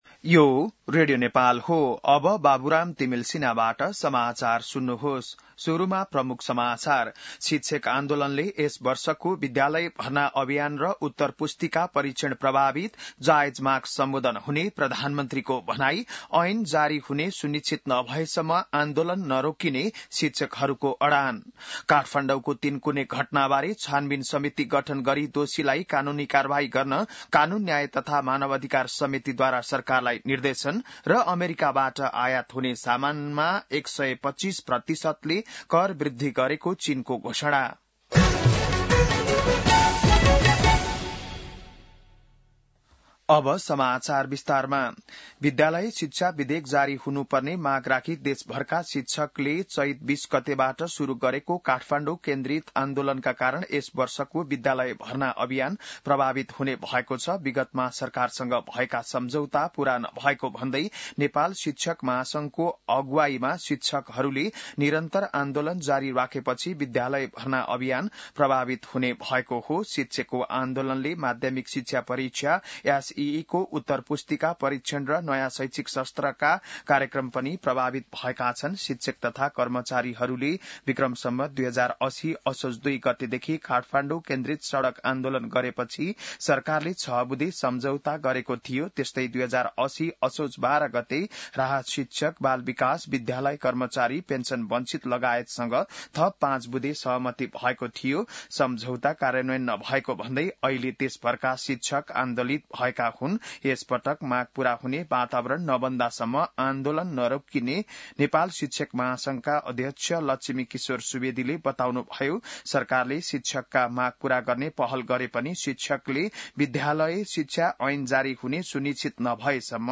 बिहान ९ बजेको नेपाली समाचार : ३० चैत , २०८१